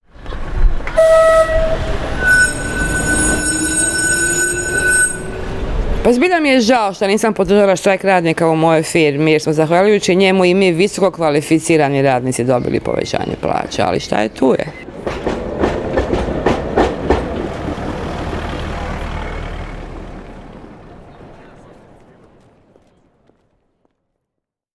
radio intervention
STATEMENTS BROADCASTED ON RADIO "SLJEME":